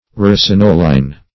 Search Result for " ricinolein" : The Collaborative International Dictionary of English v.0.48: Ricinolein \Ric`in*o"le*in\, n. [L. ricinus castor-oil plant + oleum oil.]